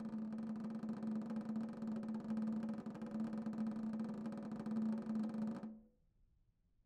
Snare2-rollNS_v1_rr1_Sum.wav